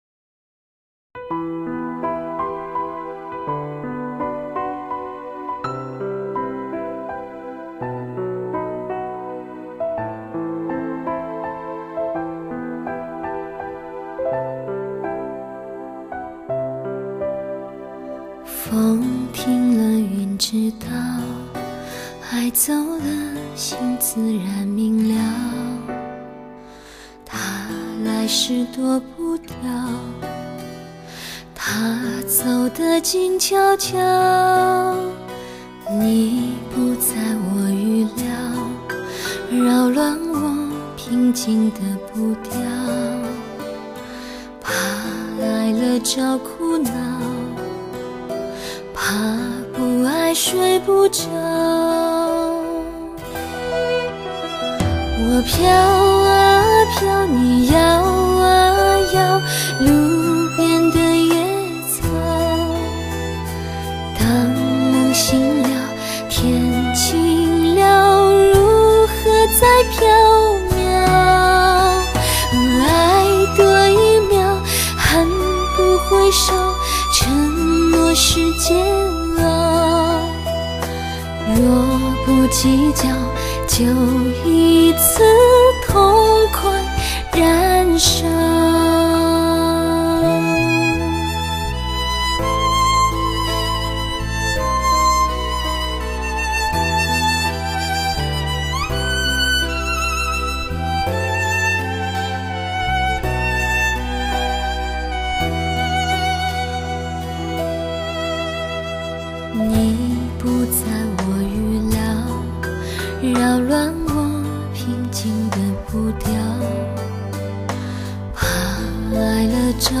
芬芳纯美的声音，情浓意厚的乐韵，将带给你无限美妙的憧憬。